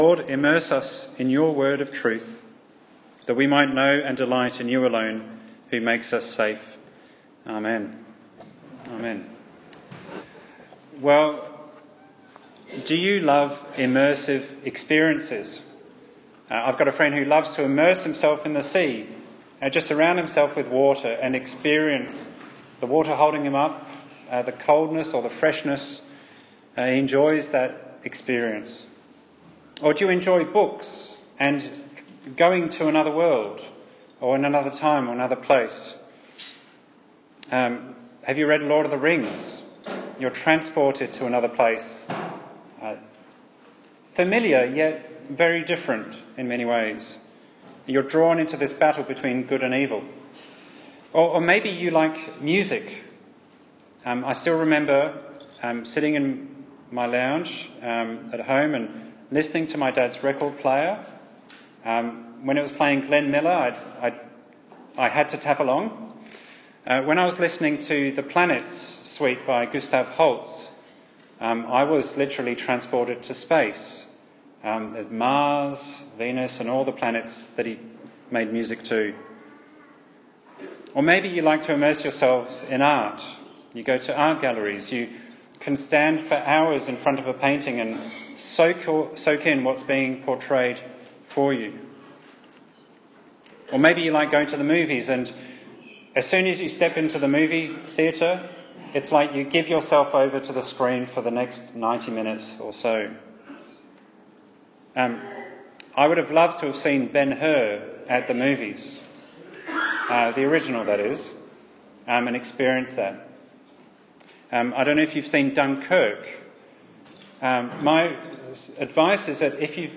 Psalm 4 Service Type: Sunday morning service Bible Text